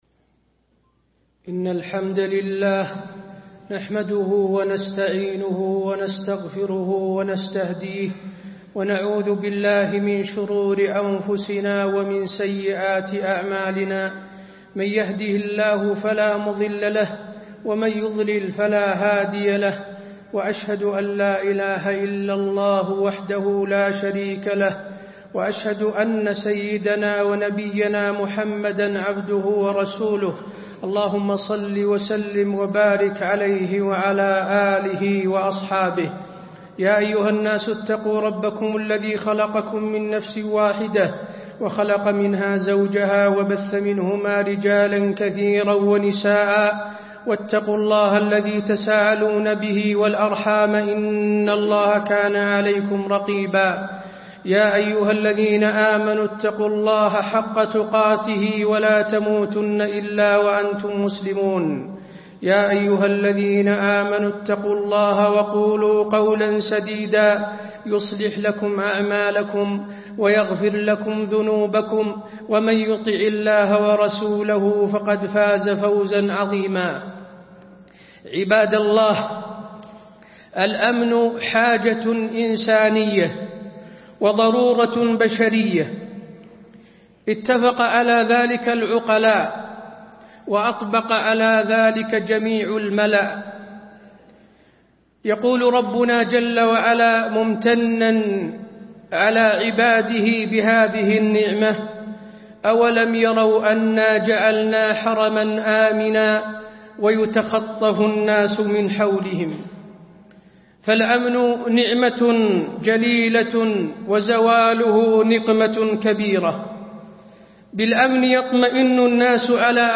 تاريخ النشر ١٠ صفر ١٤٣٥ هـ المكان: المسجد النبوي الشيخ: فضيلة الشيخ د. حسين بن عبدالعزيز آل الشيخ فضيلة الشيخ د. حسين بن عبدالعزيز آل الشيخ أثر الأمن في حياة المجتمع